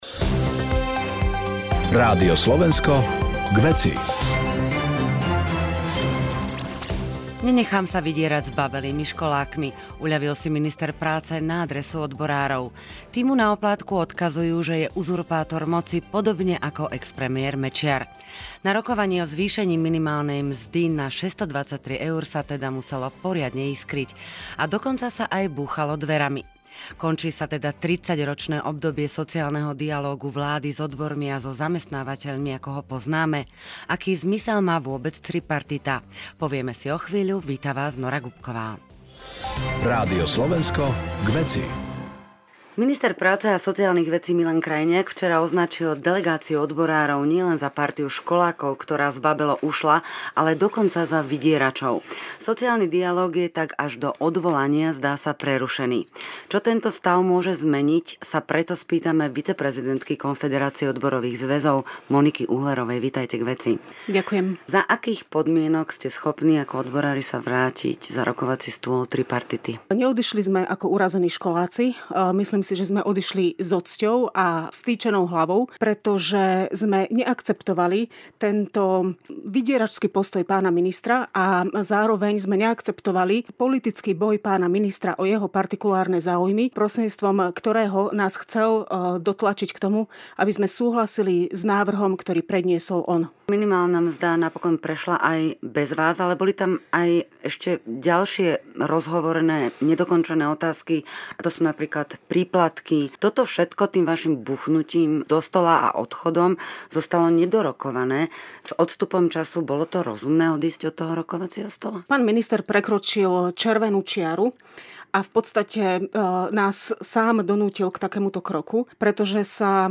diskusnej relácii K veci